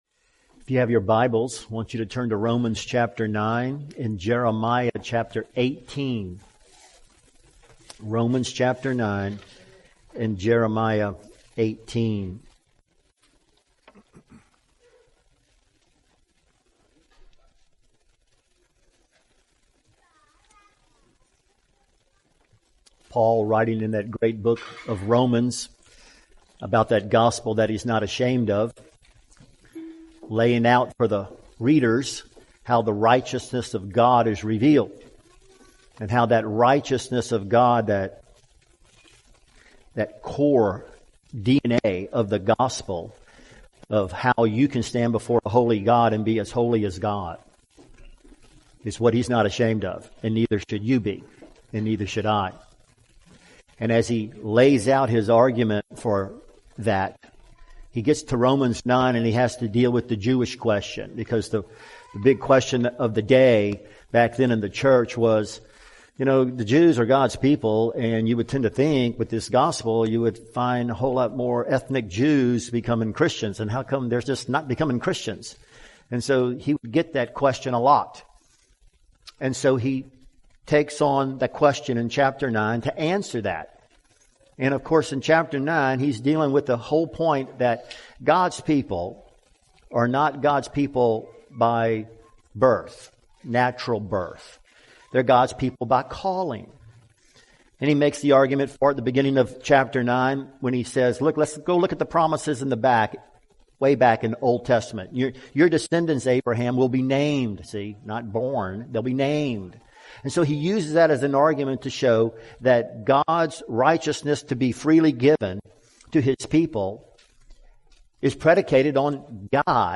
Full Sermons